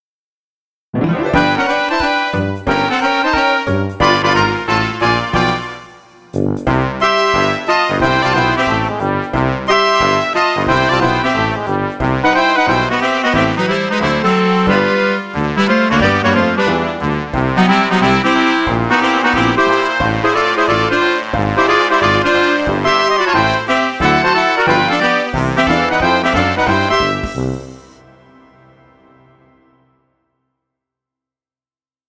(In orchestration. No video.)